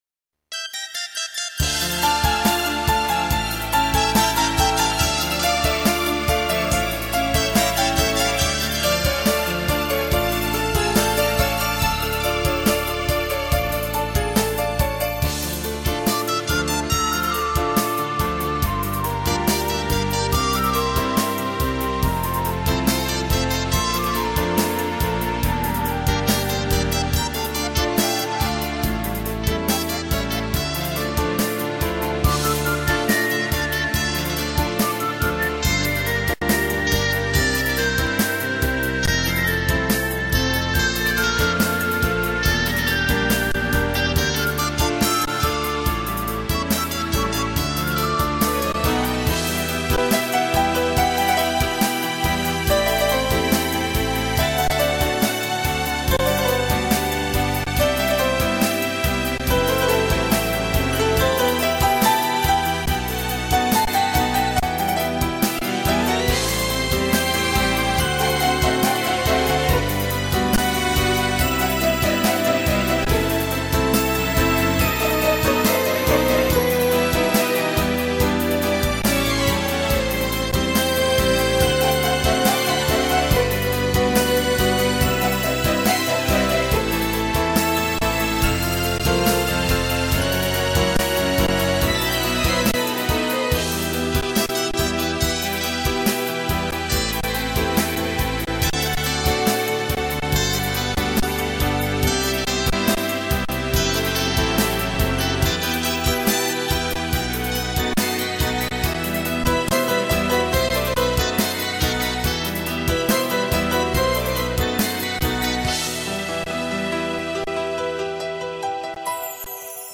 детской песни